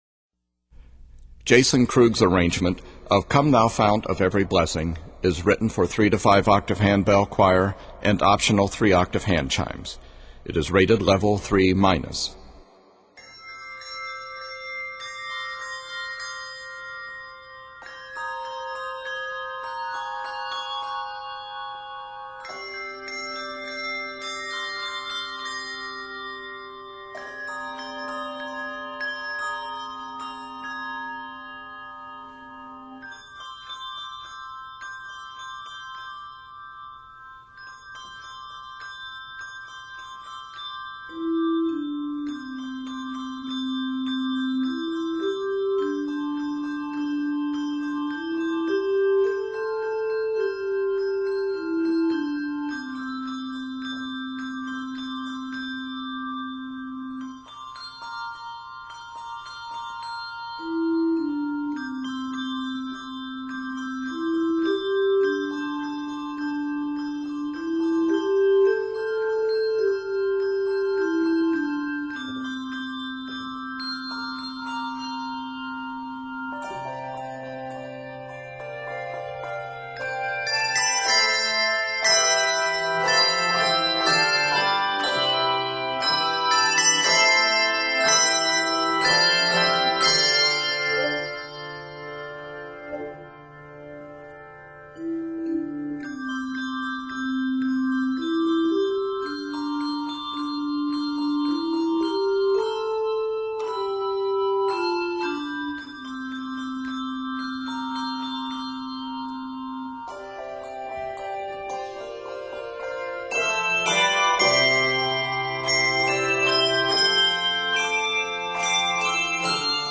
Reflective and poignant
Octaves: 3-5